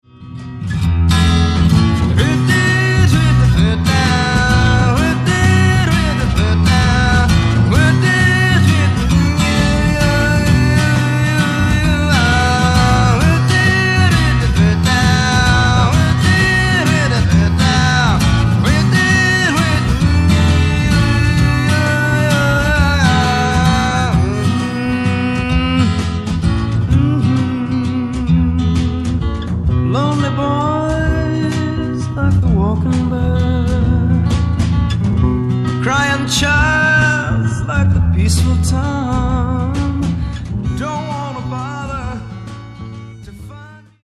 ジャズ、カントリー、ソウルなどを絶妙にブレンドして作られたサウンドはヒップかつクール、とにかく洗練されている。